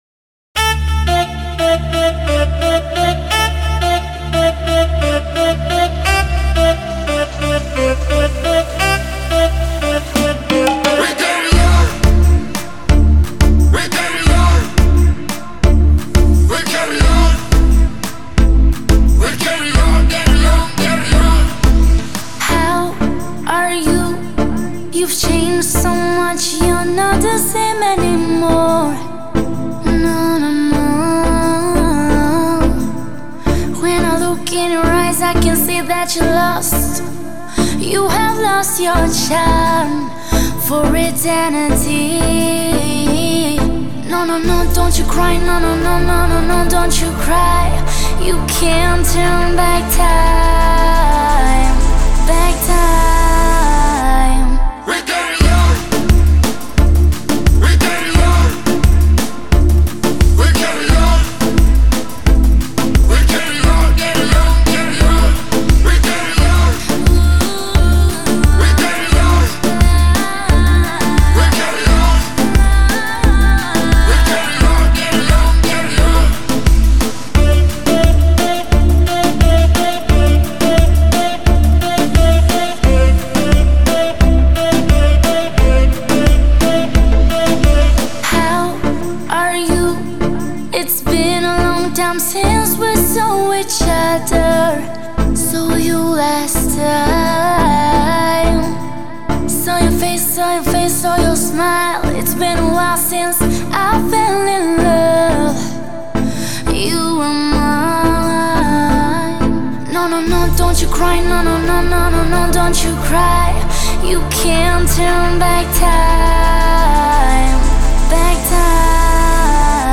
это вдохновляющая композиция в жанре поп